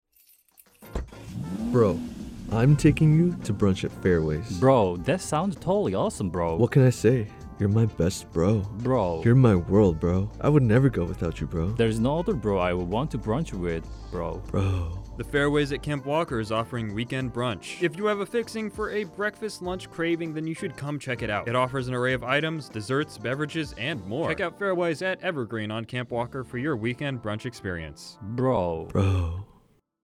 Evergreen Brunch Spotlight Radio Commercial